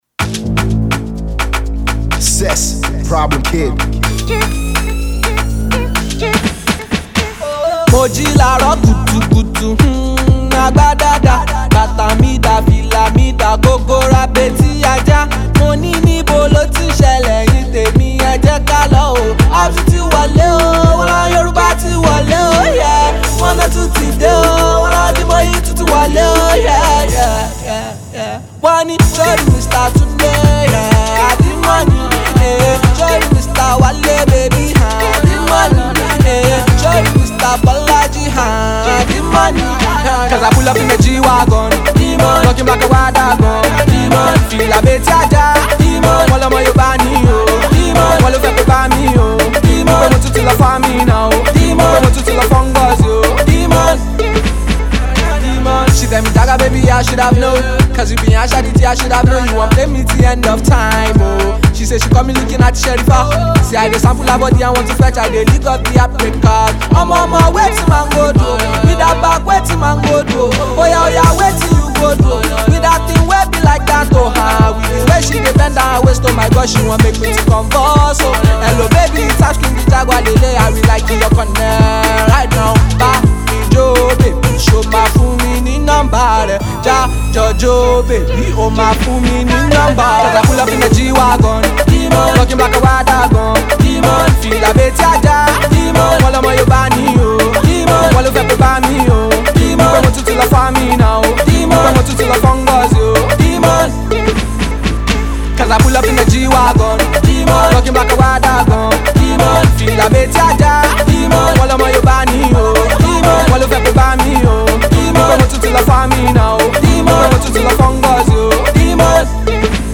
Afro-pop
a groovy jam